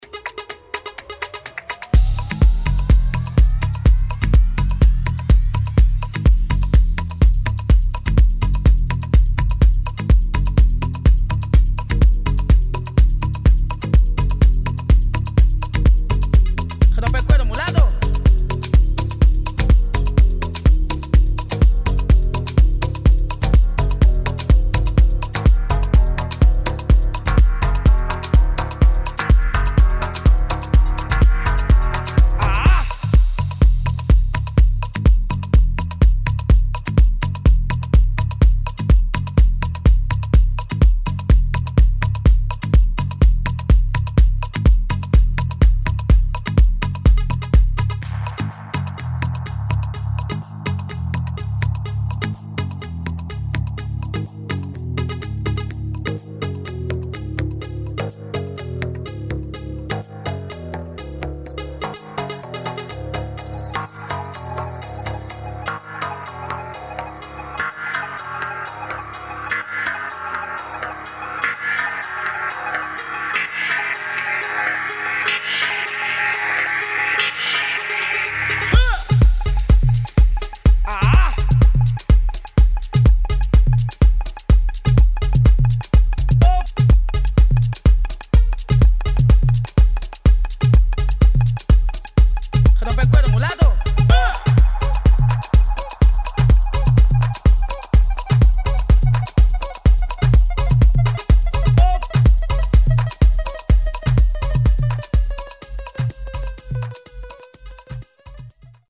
[ JAZZ | CUBA | DEEP HOUSE ]